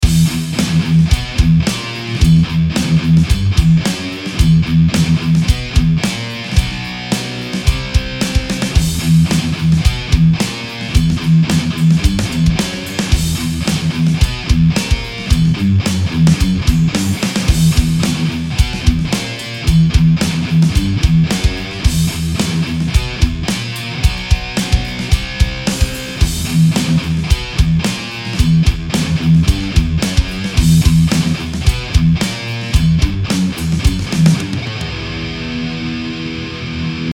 ������� ��������� SQUIER AFFINITY STRATOCASTER